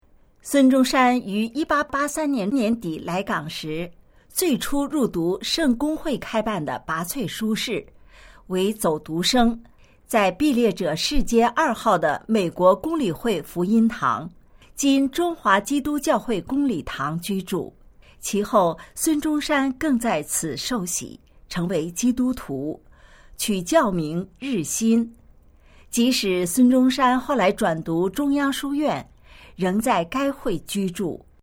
语音简介